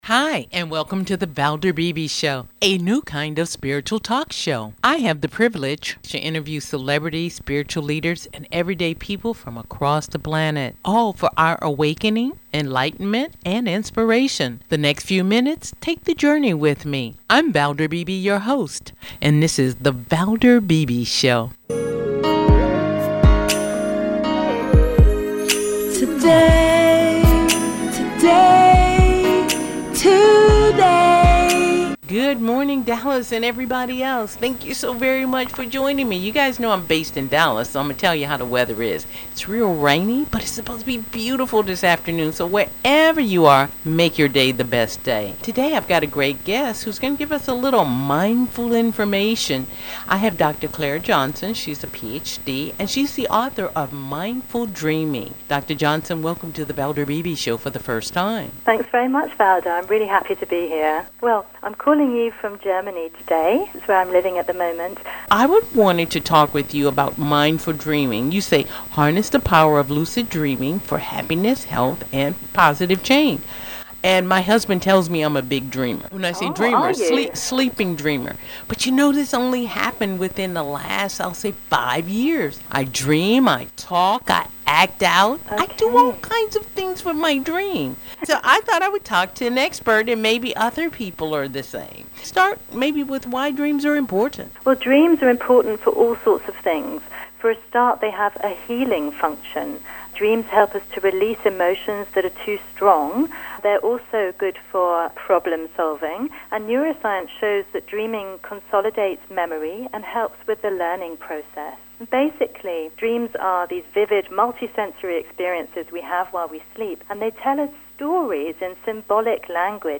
US radio interview – why are dreams important?